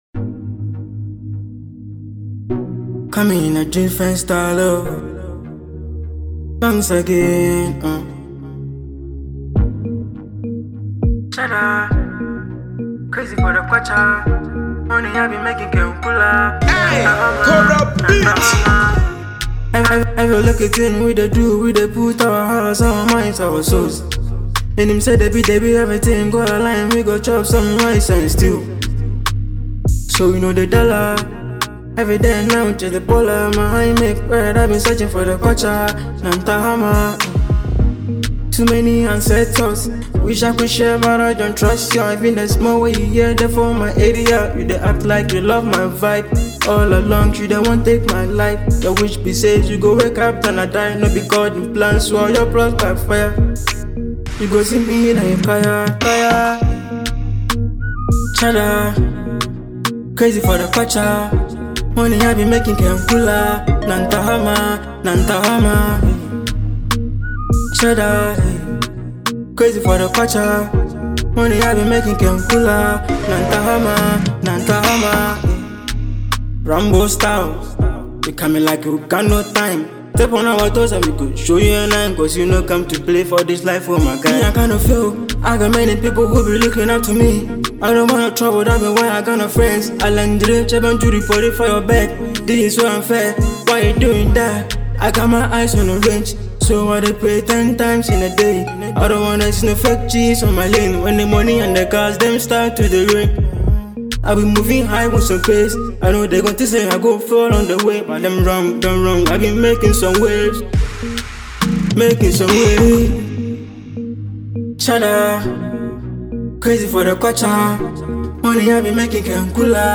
an upcoming Afro-pop singer